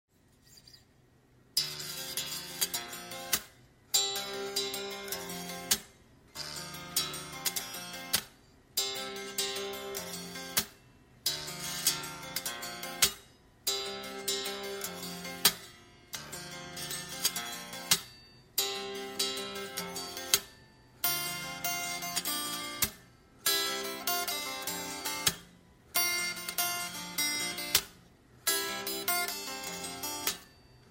この度スケッチとしてボイスメモで録音したギターフレーズを例に解説させていただいます。